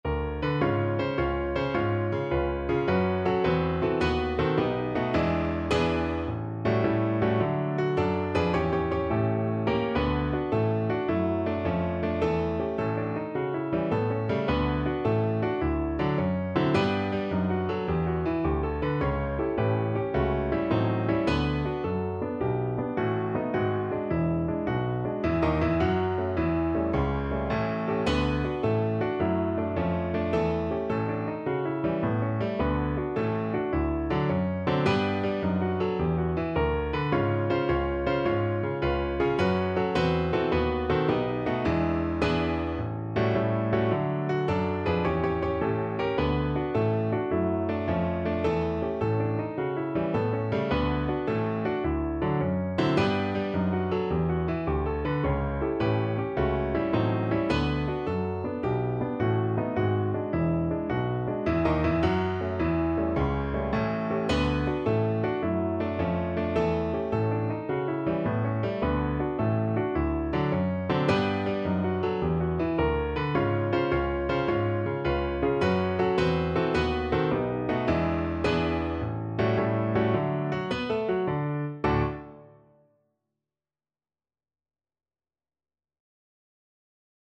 Con Spirito . = c. 106
6/8 (View more 6/8 Music)
Pop (View more Pop Violin Music)